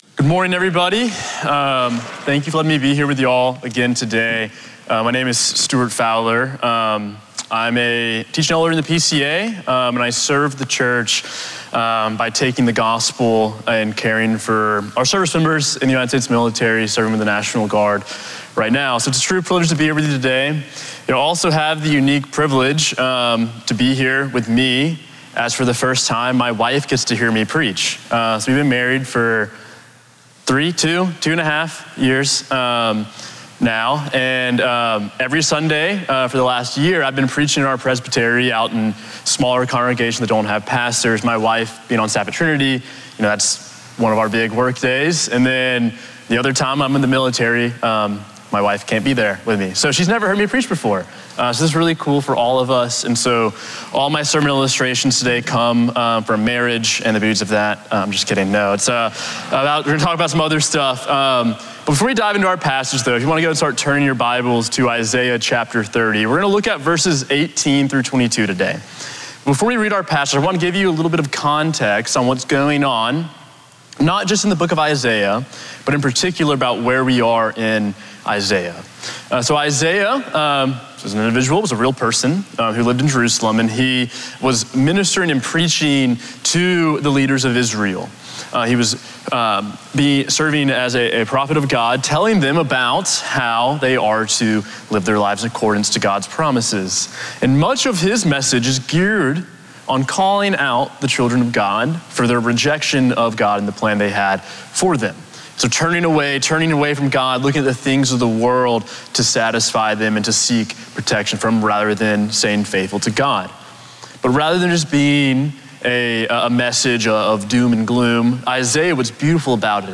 Sermon Audio from Sunday
Sermon on Isaiah 30:18-22 from November 16